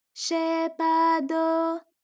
ALYS-DB-002-JPN - Source files of ALYS’ first publicly available Japanese vocal library, initially made for Alter/Ego.